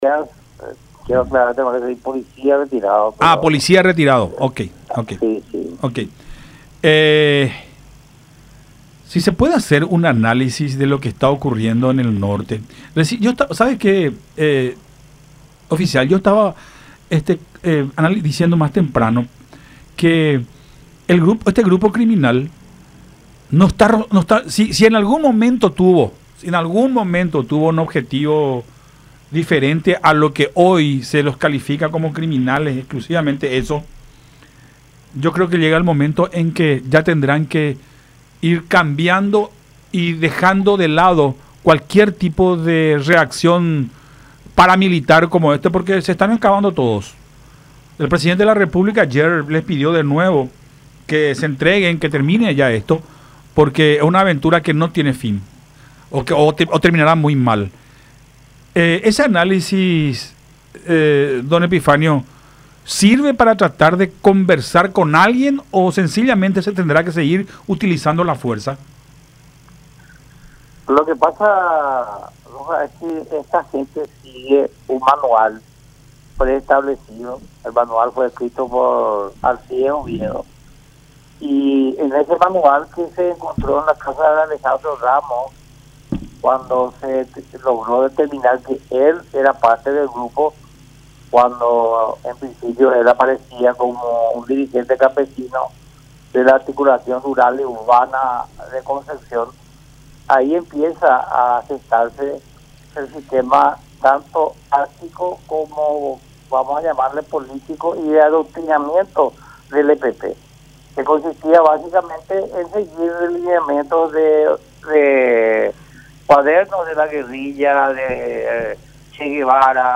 en charla con Nuestra Mañana por Unión TV y radio La Unión